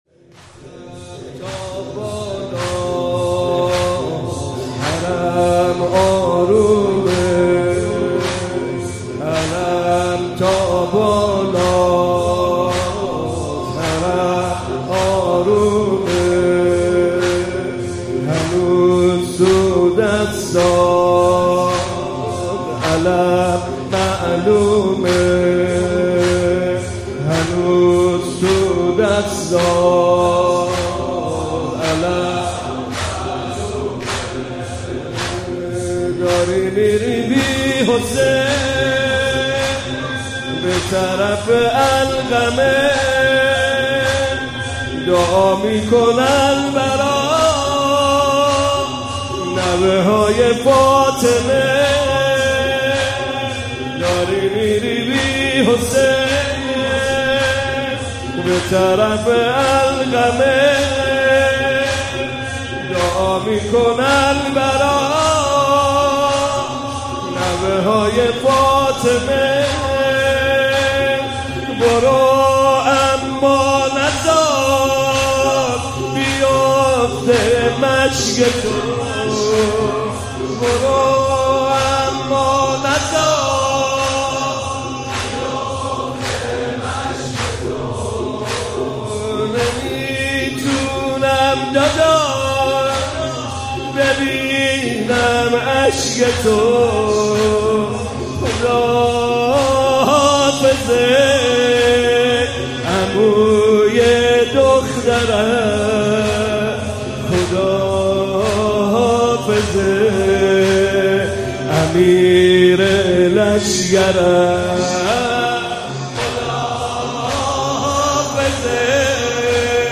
مداحی جدید حاج محمدرضا طاهری شب نهم محرم97 شب تاسوعا هیئت مکتب الزهرا